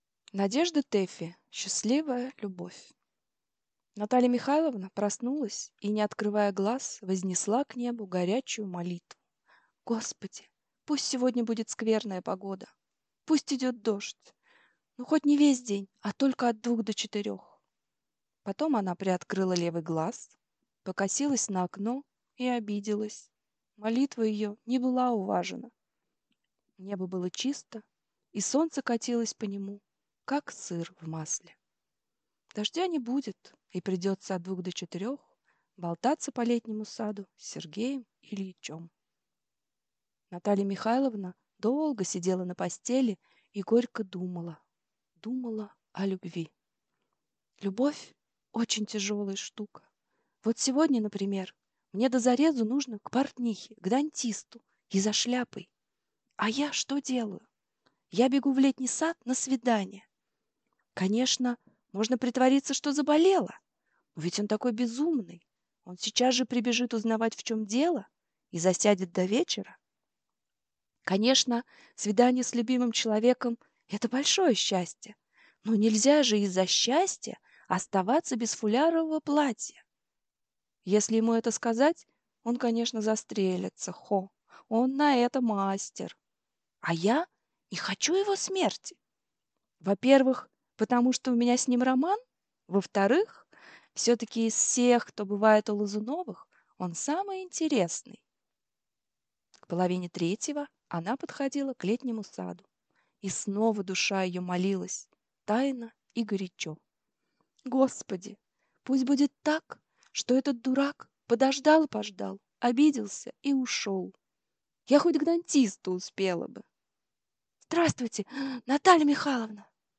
Аудиокнига Счастливая любовь | Библиотека аудиокниг